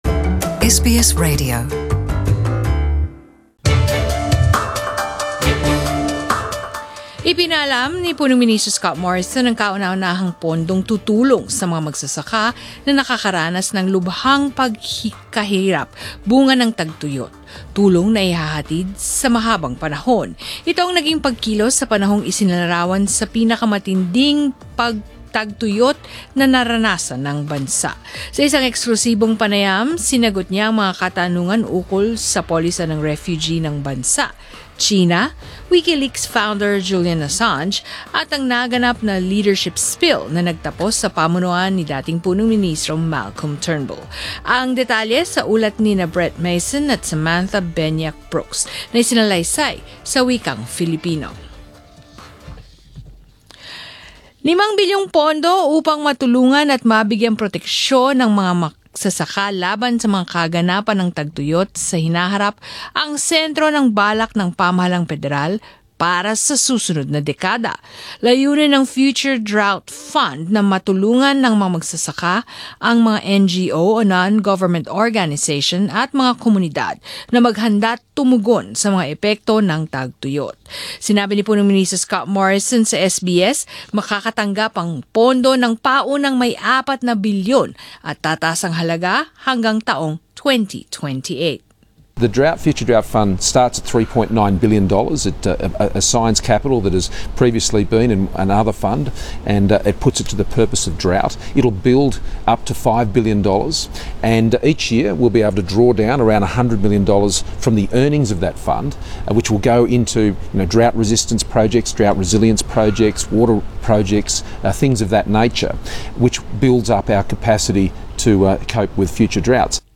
Prime Minister Scott Morrison in an interview with SBS Source: SBS
In an exclusive interview with SBS, Mr Morrison has also spoken on Australia's refugee policy, China, Wikileaks founder Julian Assange and the leadership spill that toppled former prime minister Malcolm Turnbull.